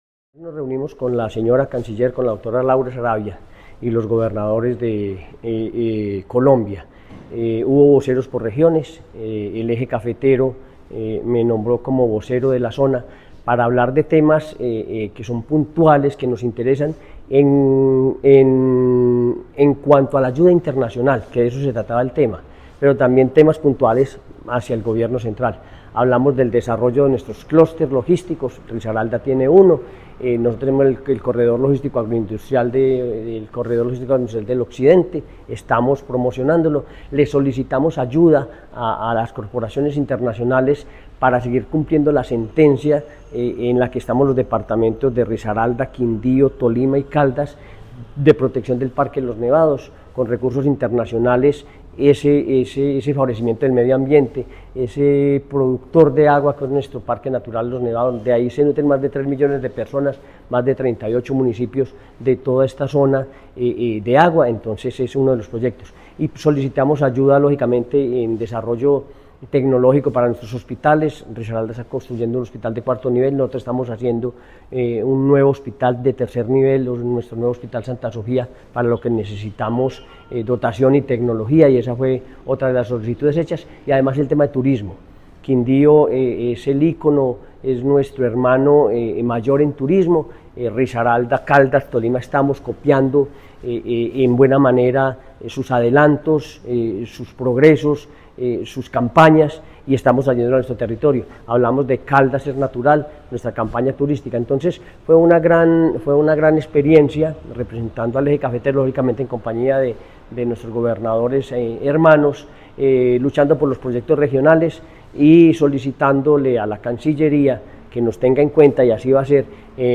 Henry Gutiérrez Ángel, gobernador de Caldas (Encuentro de Cooperación Internacional)